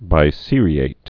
(bī-sērē-ĭt, -āt)